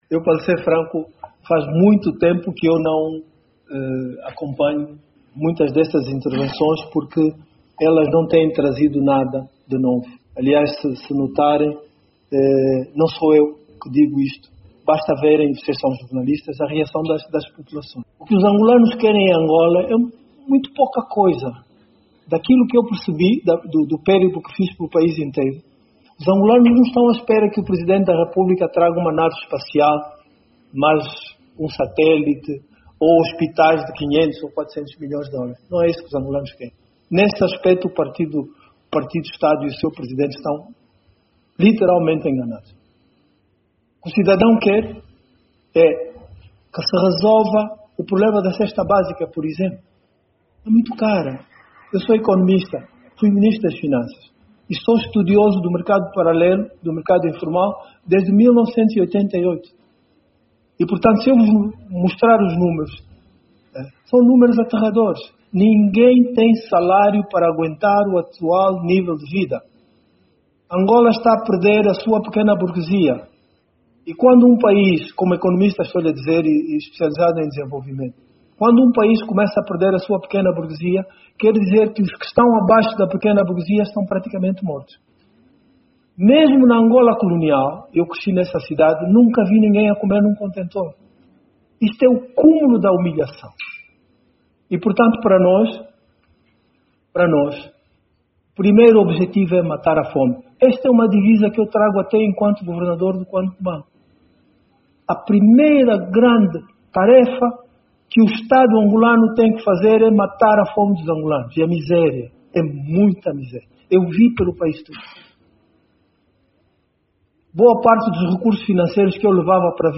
Júlio Bessa, que reagia nesta sexta-feira, 17, em conferência de imprensa, ao discurso sobre o “Estado da Nação”, proferido pelo Presidente da República, João Lourenço, na Assembleia Nacional, lamentou o aumento da pobreza e da miséria no seio das famílias angolanas perante uma “governação desastrosa e insensível” do partido no poder.